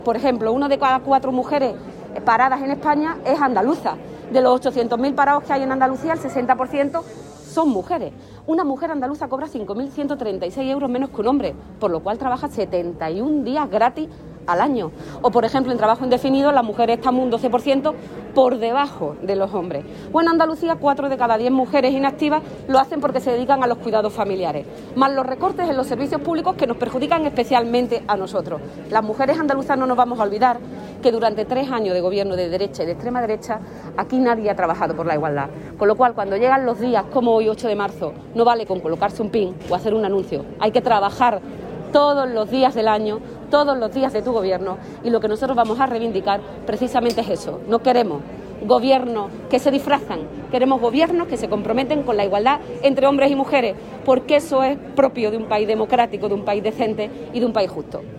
Acto de UGT por el 8 de Marzo
Cortes de sonido